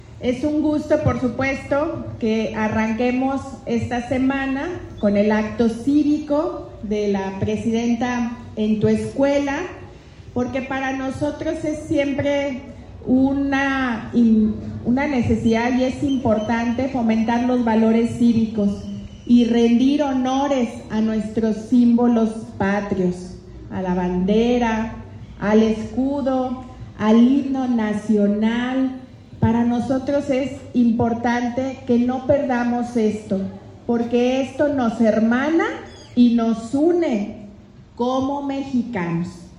AudioBoletines
Lorena Alfaro, Presidenta de Irapuato